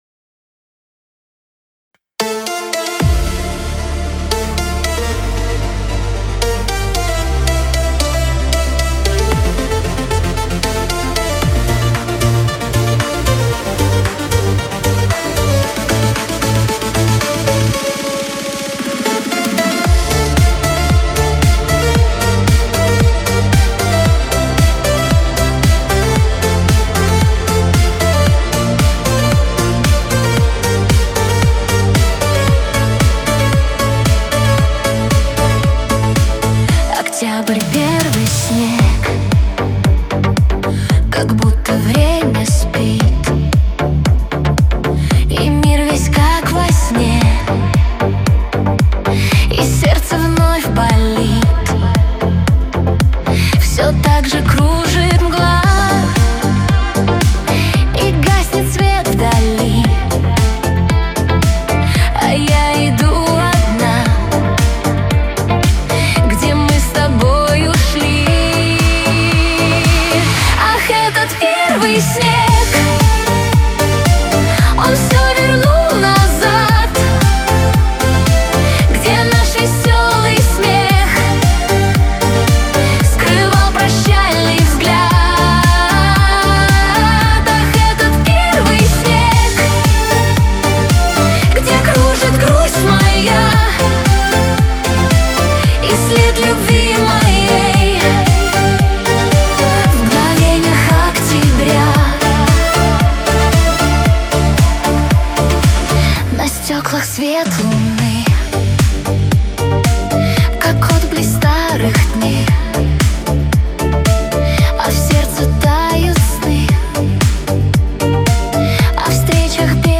созданный с помощью искусственного интеллекта.
Русская AI музыка